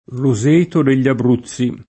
roseto [ro@%to] s. m. — anche top.: Roseto degli Abruzzi [